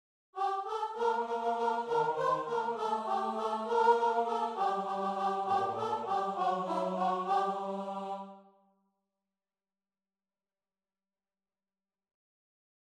Фрагмент некого дуэта, вероятно из оперного произведения